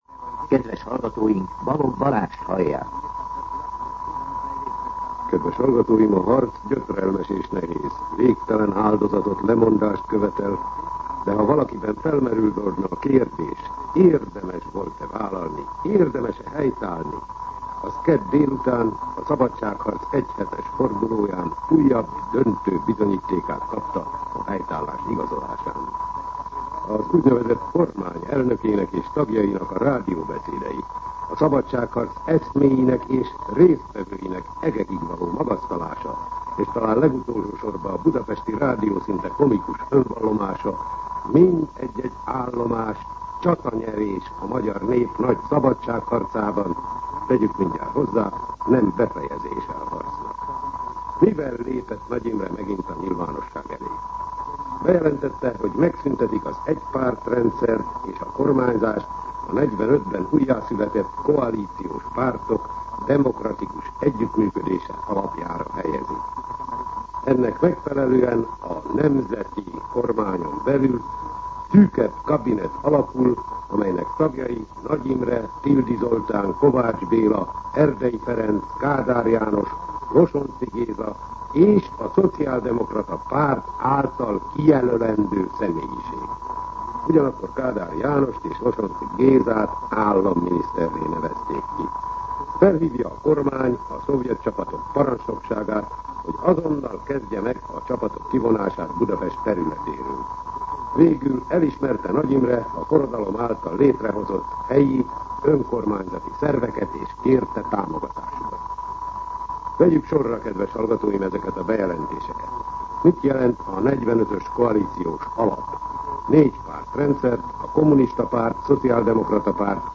Rendkívüli kommentár
Szignál